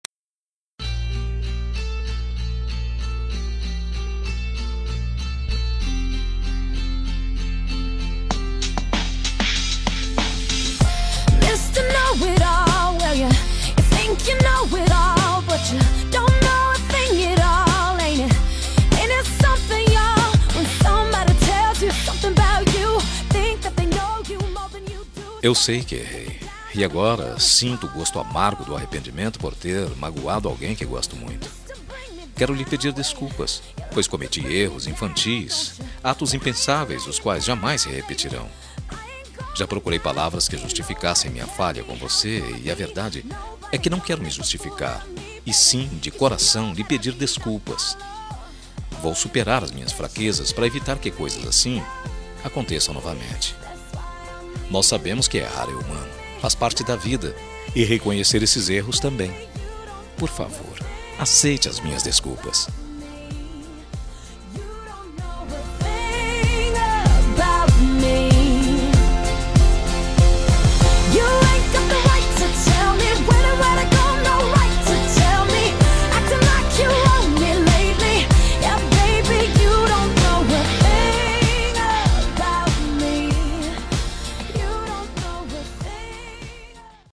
Telemensagem de Desculpas – Voz Masculina – Cód: 417